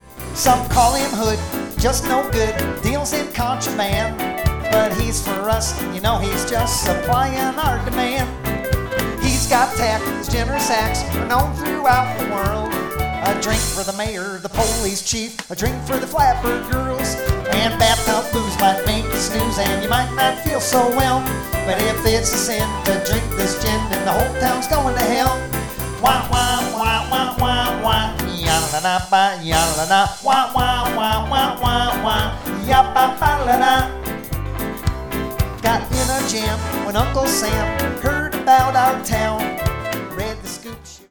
1920's, Charleston style song